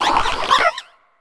Cri de Terracool dans Pokémon HOME.